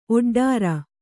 ♪ oḍḍāra